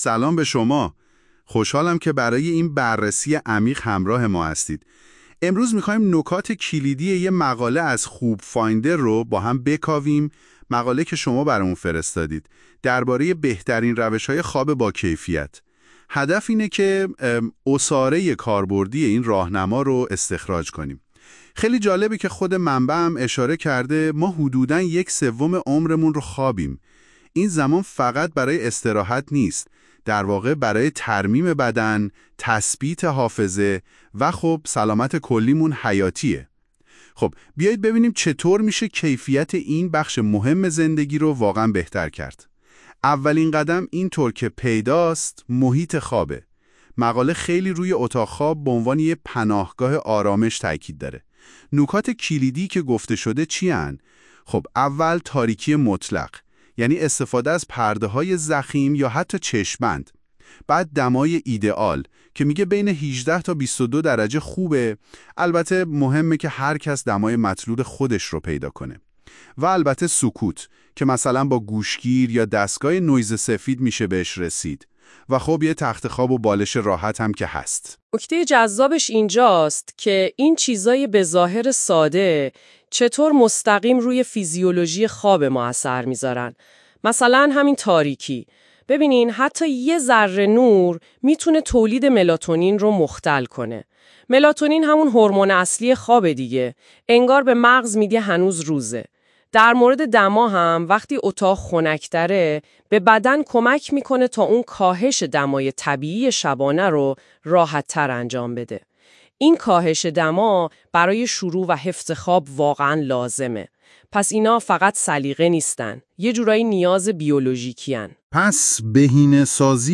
🎧 خلاصه صوتی بهترین روش ها برای خواب با کیفیت
این خلاصه صوتی به صورت پادکست و توسط هوش مصنوعی تولید شده است.